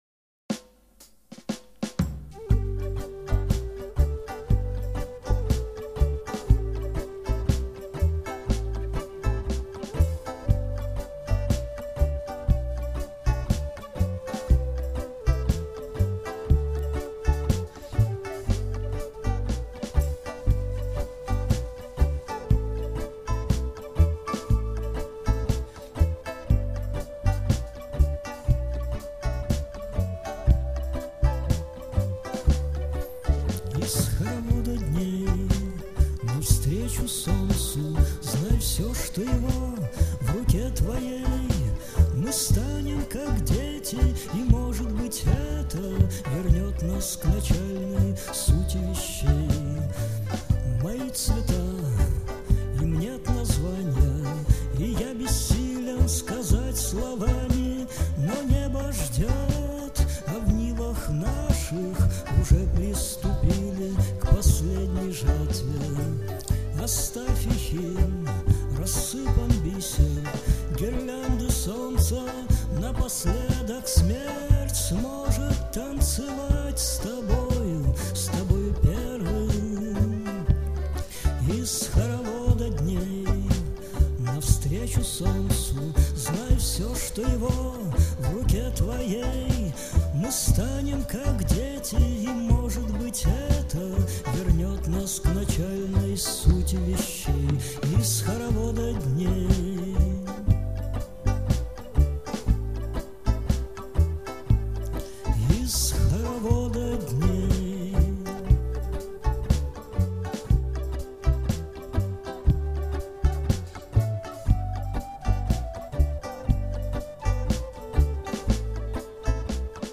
Арт-рок и авангард.